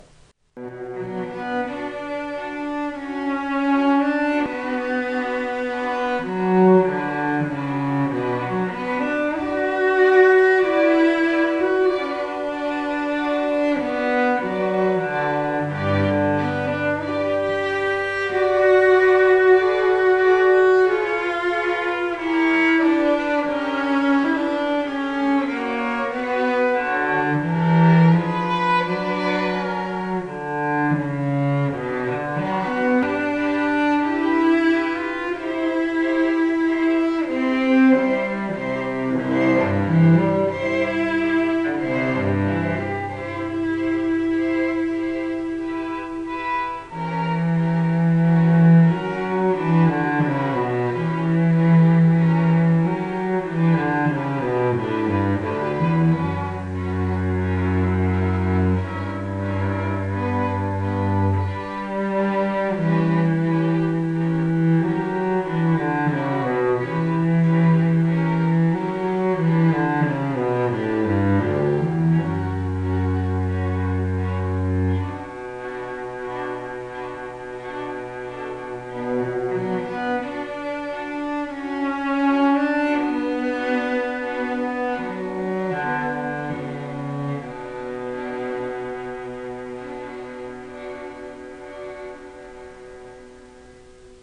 The Scottsdale String Quartet
String Quartet